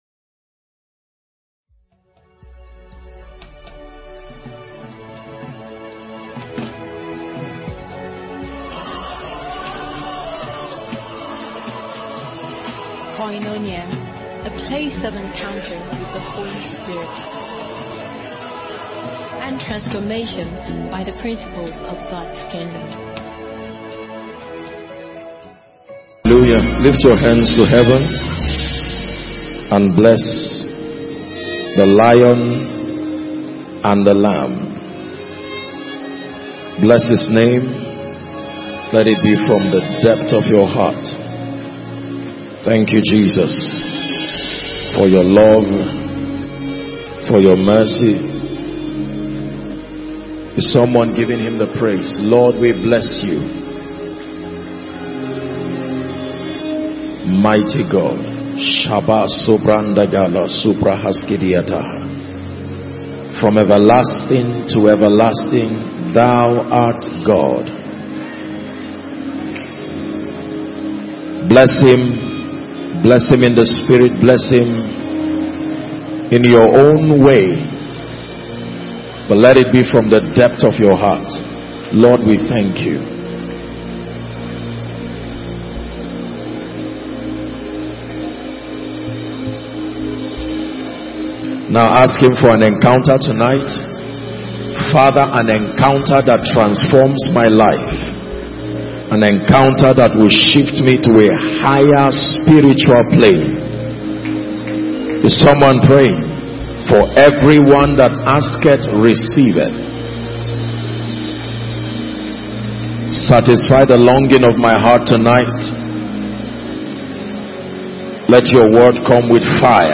Koinonia Abuja Message